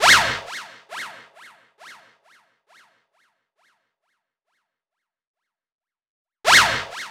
Jfx Fx.wav